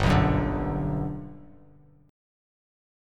Listen to GM7 strummed